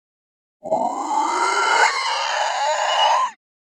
Play, download and share Zombie1 original sound button!!!!
zombie1.mp3